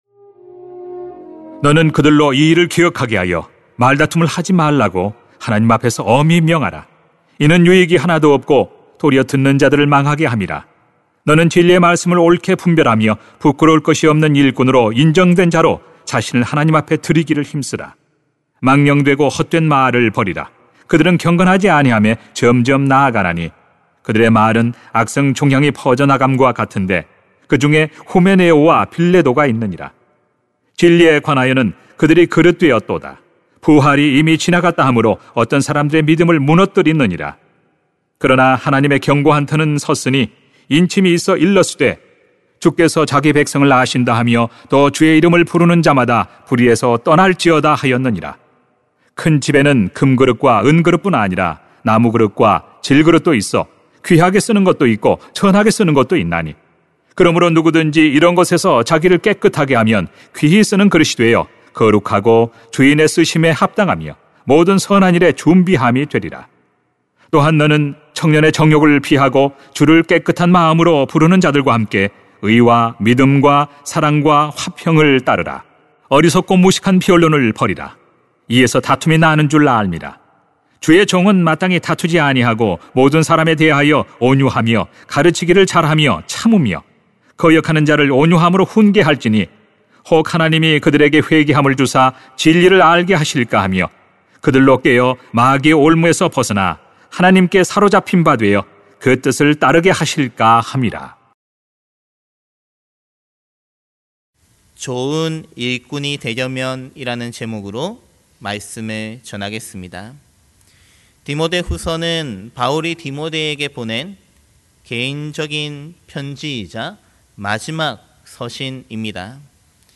[딤후 2:14-26] 좋은 일꾼이 되려면 > 새벽기도회 | 전주제자교회